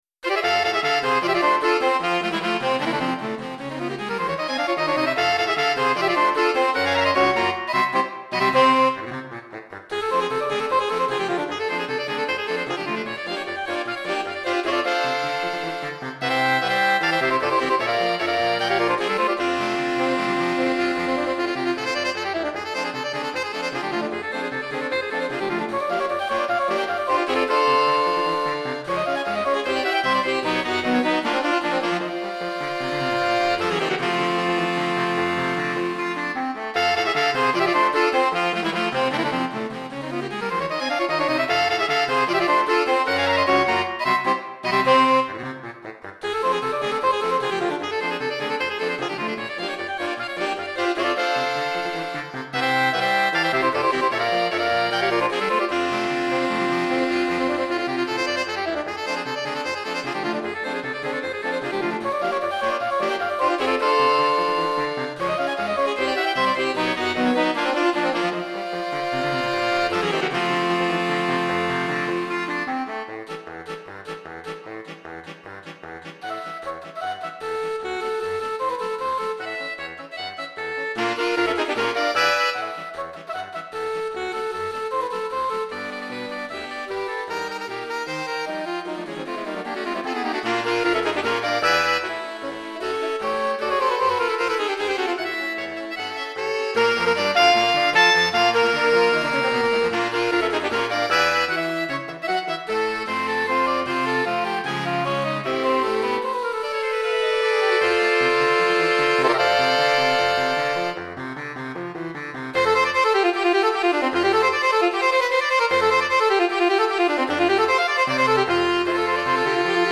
5 Saxophones